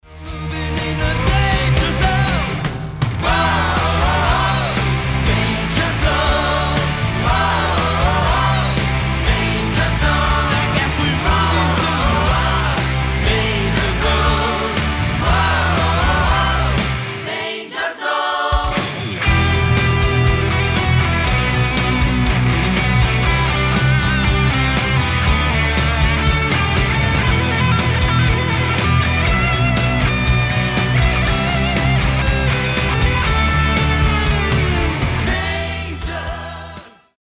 2017 Full Lenght CD (Live)